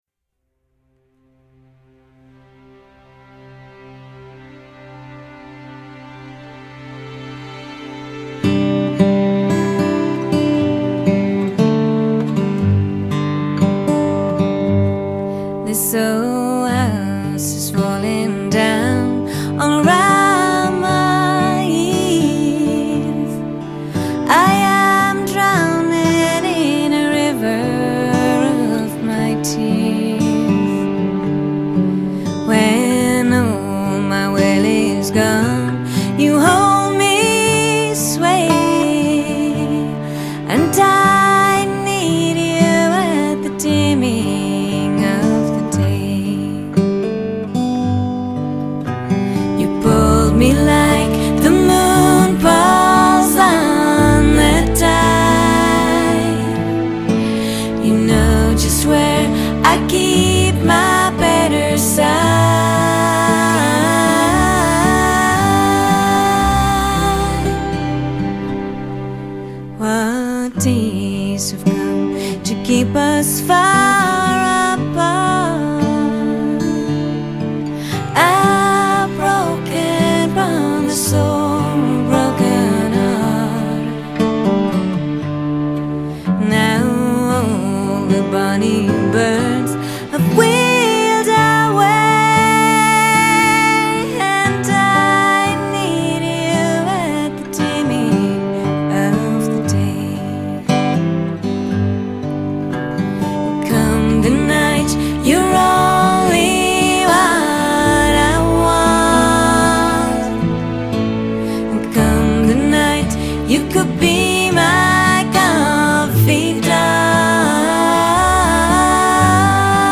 整张专辑比前几张专辑安静了许多。